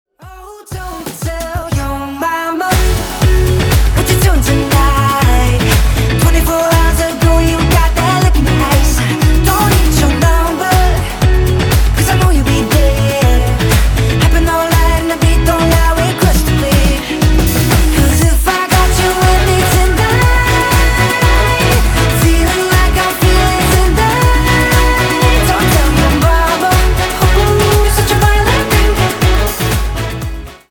• Качество: 320, Stereo
поп
мужской вокал
заводные